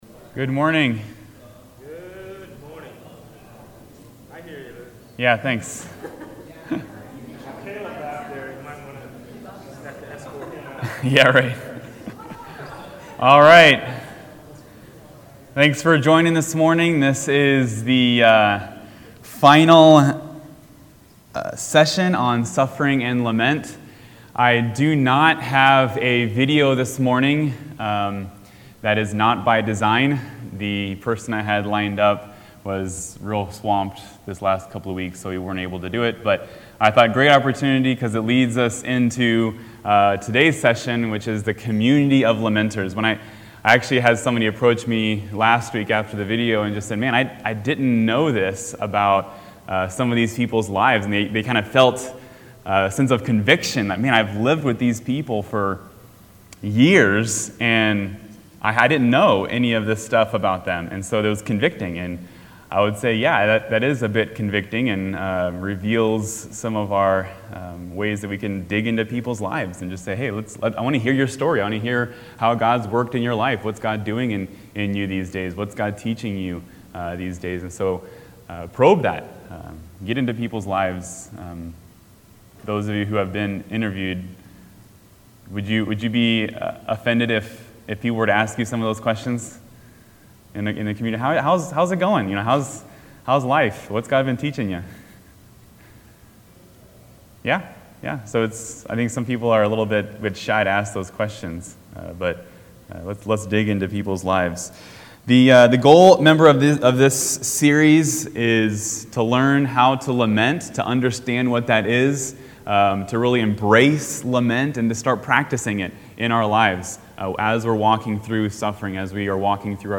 Adult Sunday School class